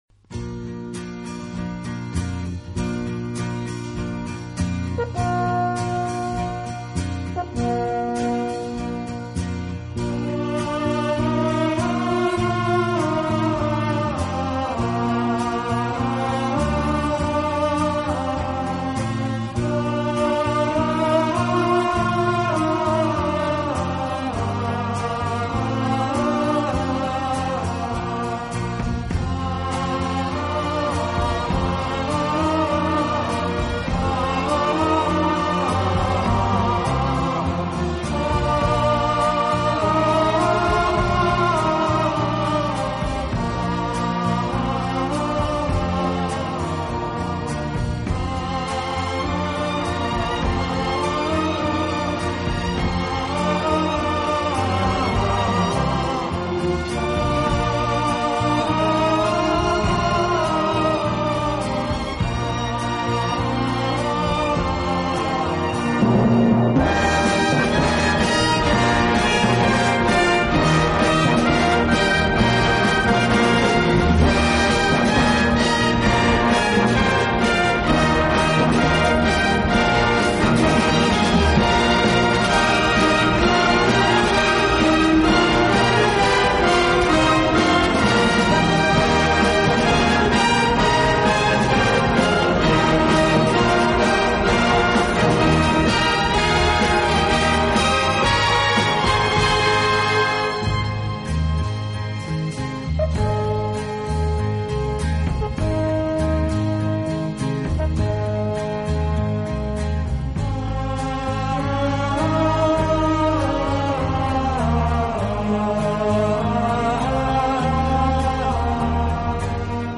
【轻音乐】
2、精心运用打击乐、尤其是一些特殊的打击乐器，小号、
3、乐队演奏以华丽著称，气势磅礴。